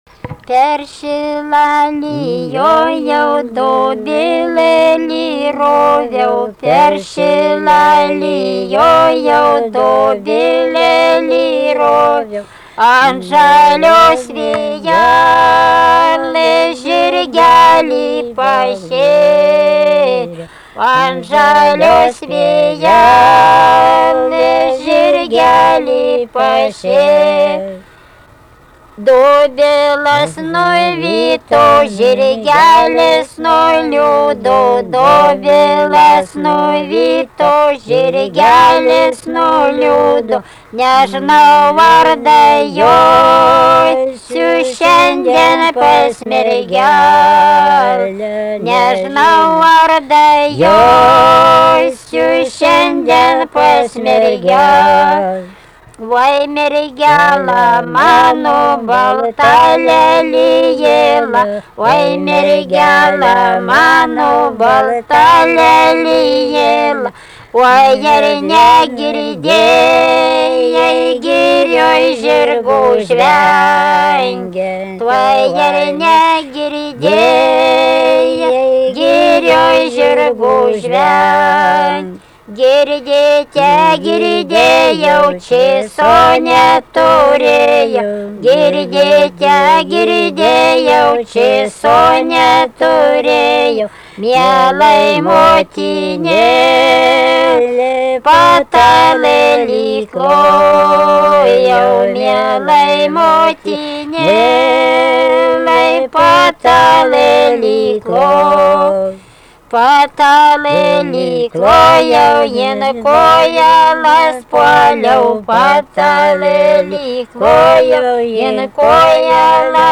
vokalinis
2 balsai